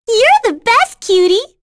Annette-Vox_Victory.wav